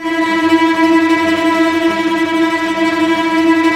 Index of /90_sSampleCDs/Roland L-CD702/VOL-1/STR_Vcs Tremolo/STR_Vcs Trem f